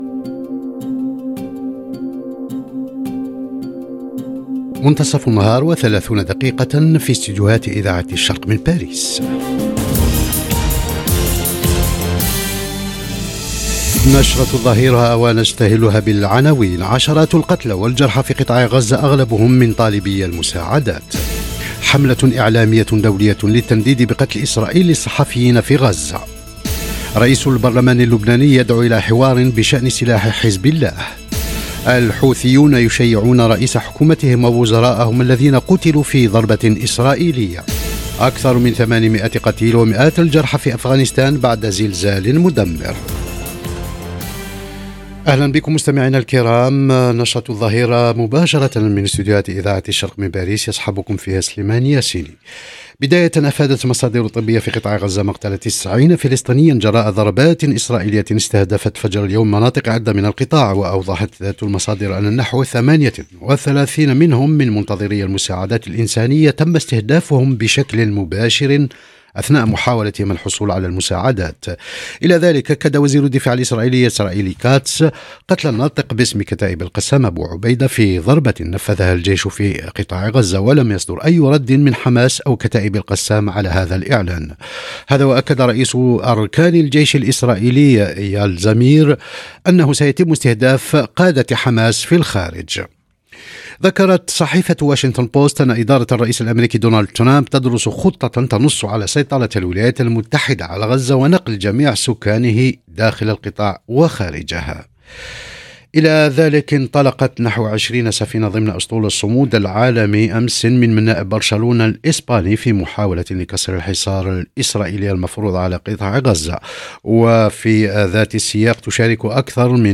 نشرة أخبار الظهيرة: مآسٍ إنسانية وصراعات متصاعدة من غزة إلى لبنان واليمن وأفغانستان - Radio ORIENT، إذاعة الشرق من باريس